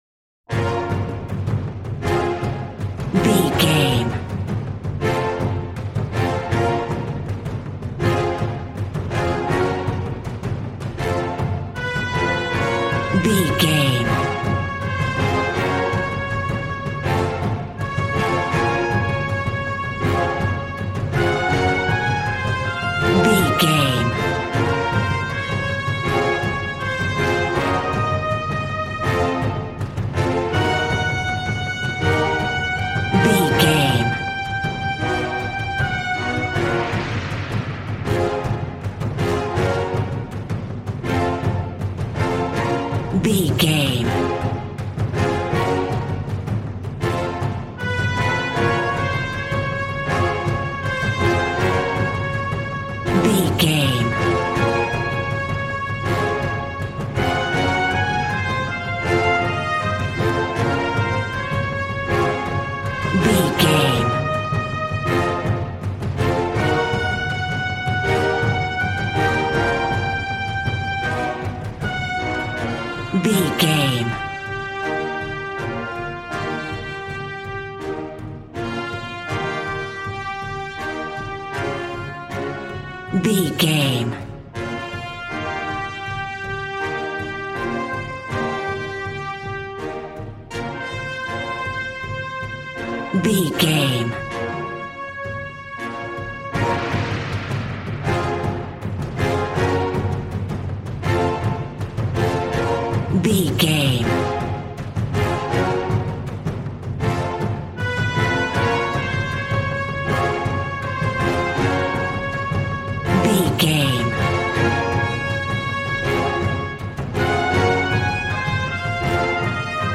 Regal and romantic, a classy piece of classical music.
Aeolian/Minor
regal
cello
double bass